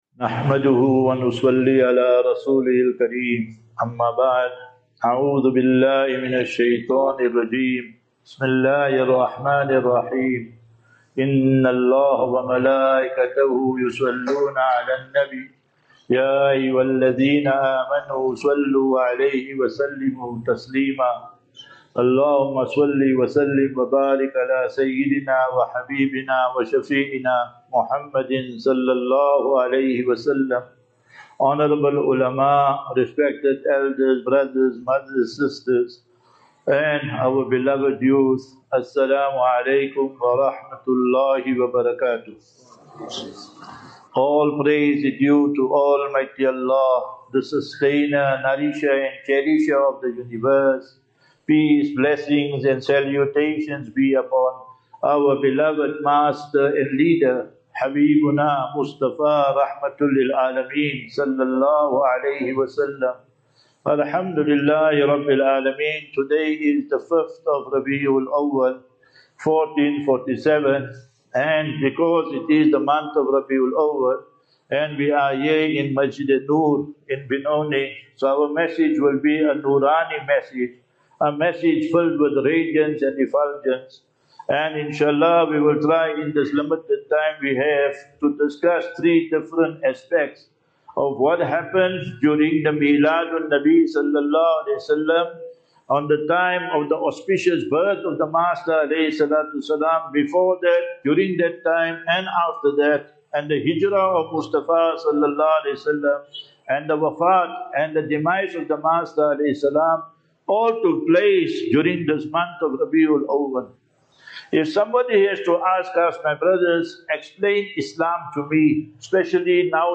29 Aug 29 August 2025 - Jumu'ah Lecture at Masjid Un-Noor, Actonville (BENONI)